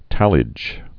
(tălĭj)